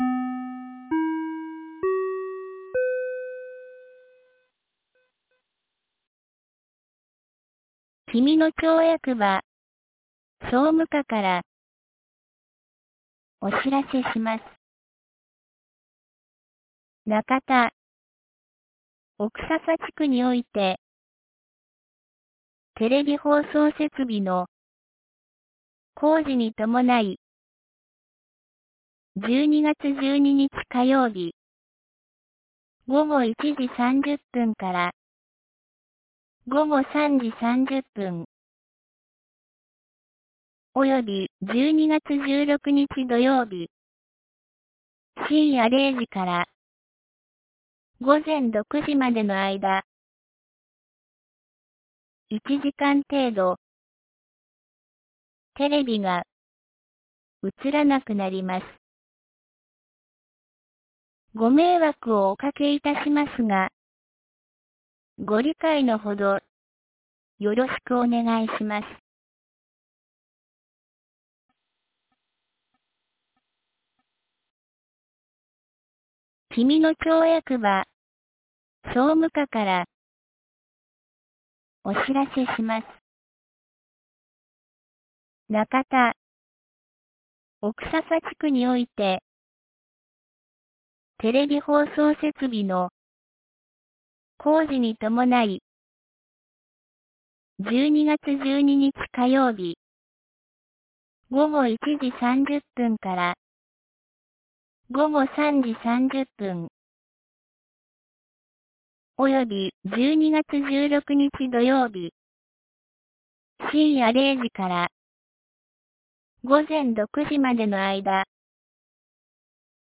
2023年12月10日 17時42分に、紀美野町より小川地区へ放送がありました。